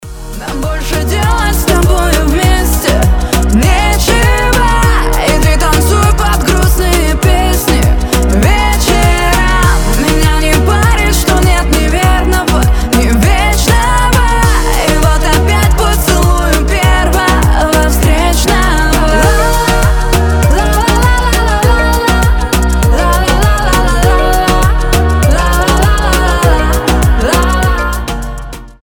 • Качество: 320, Stereo
заводные
женский голос